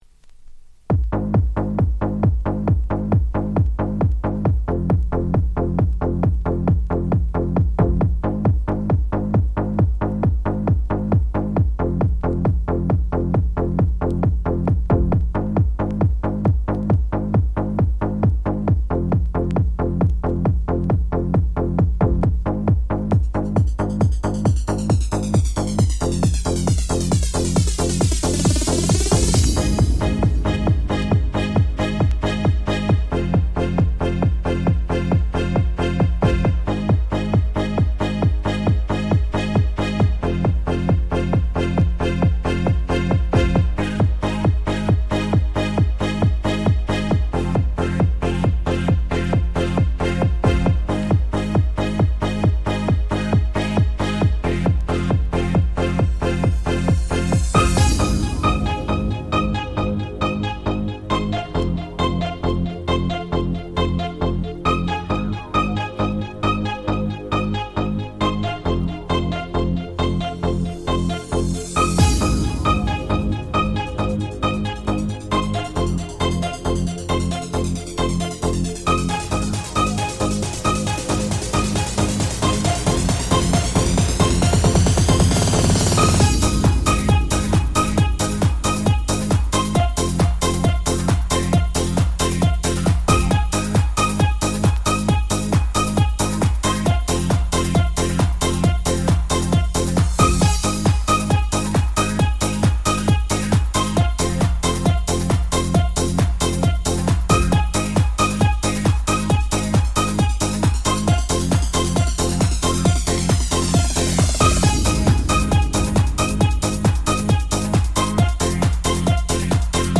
Жанр: Dream, Trance